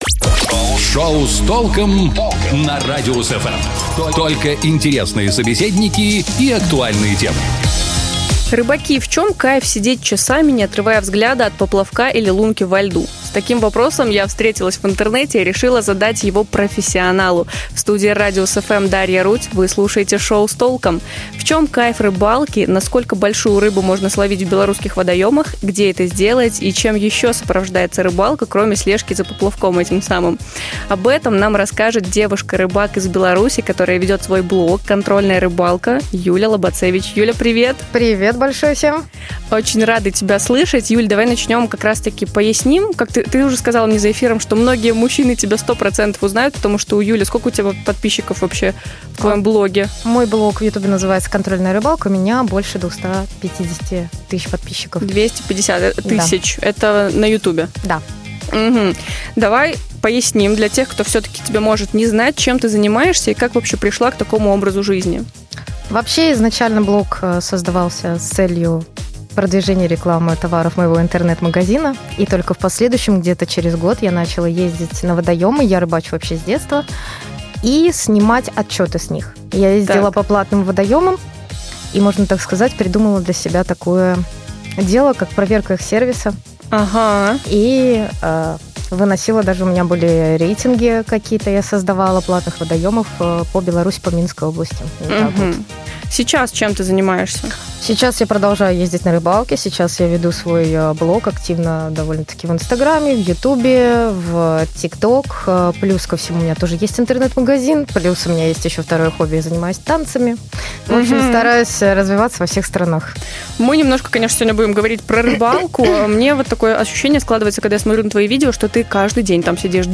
"Клёвый" эфир с девушкой-рыбаком